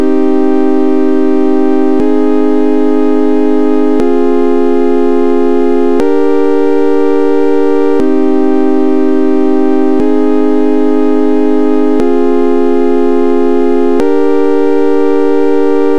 on calcule la valeur de chaque note à l’instant t (à 48 000 hz, on peut même parler de la microseconde μ) selon sa forme d’onde, ici un simple sinus.